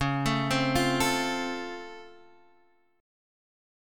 C#+M7 chord